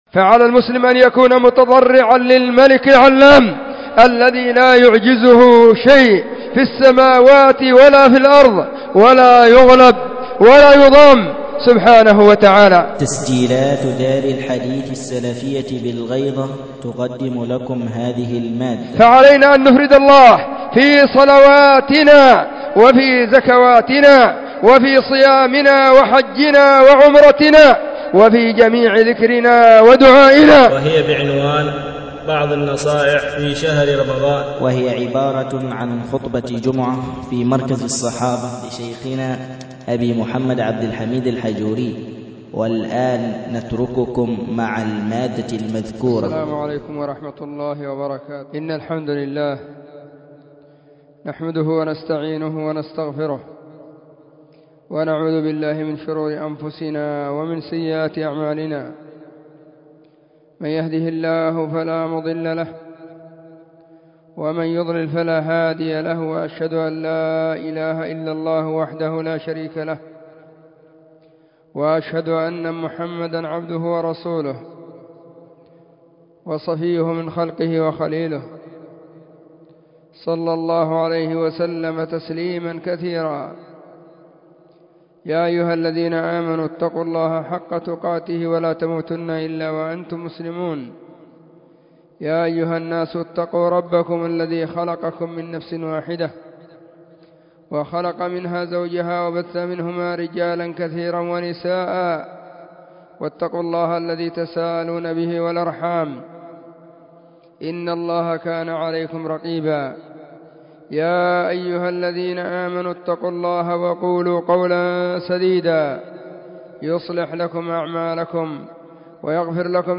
خطبة جمعة بعنوان : بعض النصائح في شهر رمضان .01/ رمضان / 1441 هجرية
📢 وكانت في مسجد الصحابة بالغيضة، محافظة المهرة – اليمن.